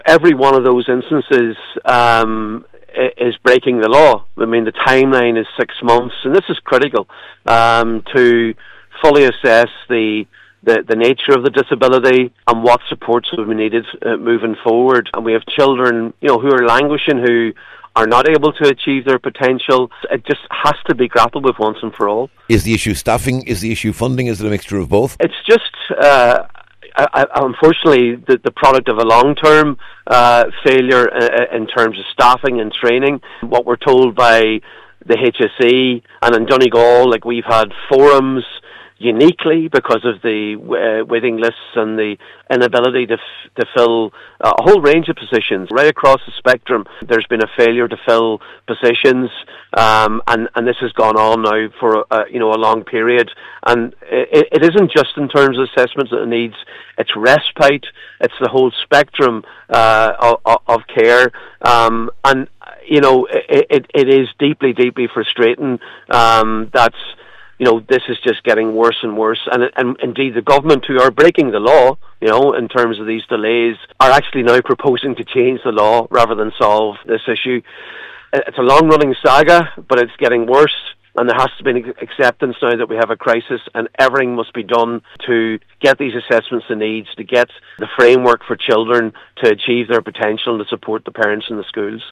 Deputy MacLochlainn says that points to a deep failure…………